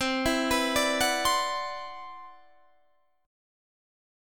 Listen to CM#11 strummed